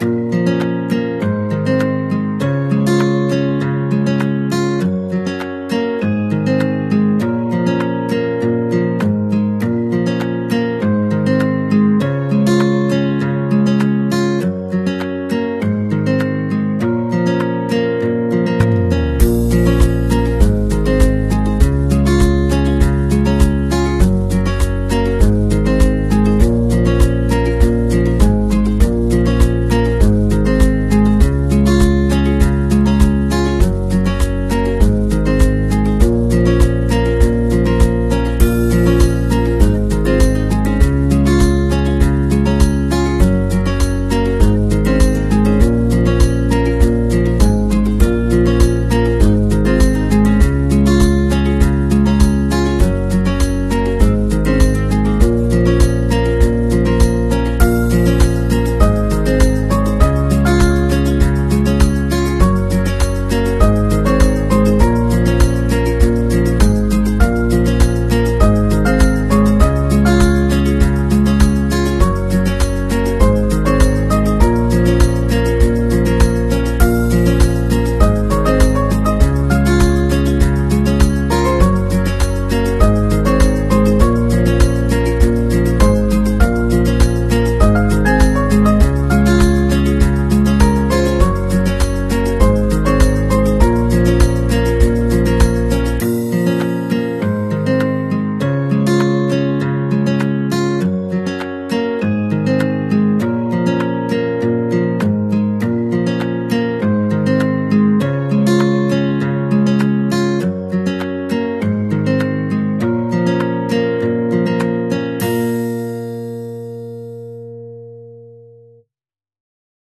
A trial run of my model train consist New Georgia excursion train for up coming train show.